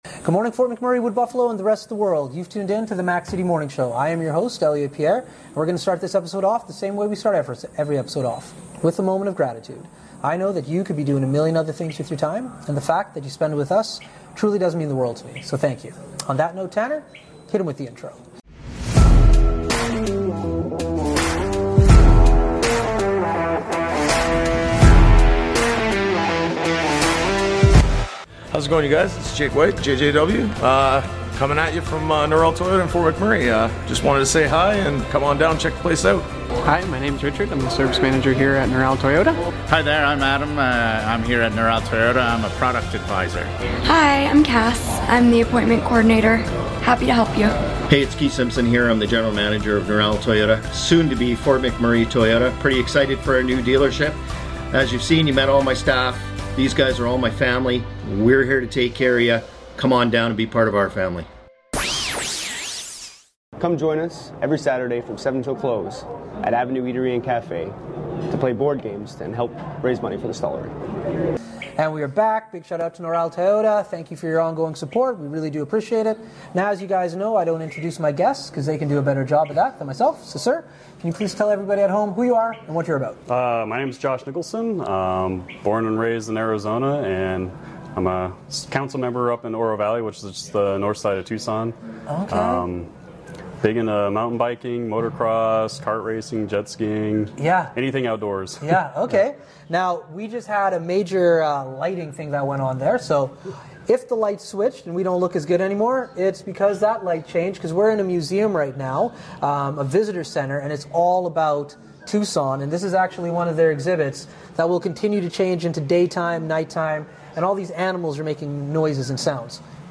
We are back with show from Tucson, Arizona! Today, we are chatting with Josh Nicholson, a council member in Tucson!